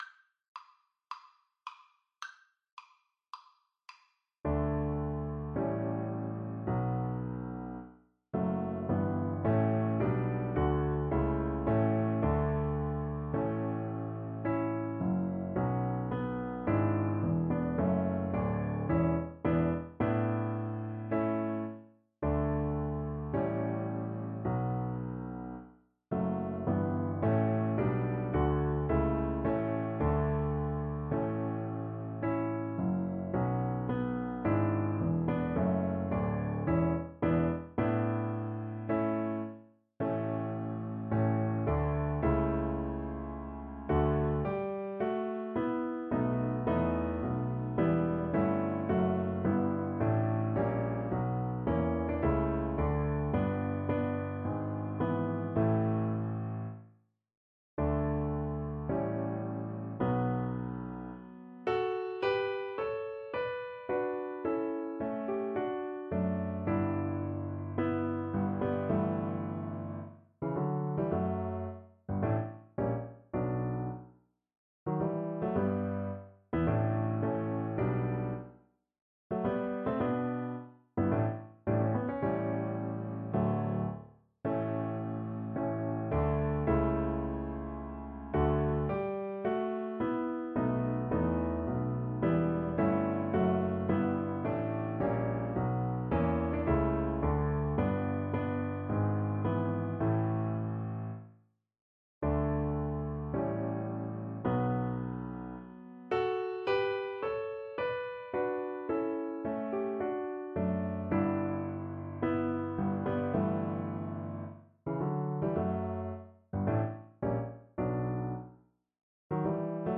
2/2 (View more 2/2 Music)
Andante = c.54
Classical (View more Classical Cello Music)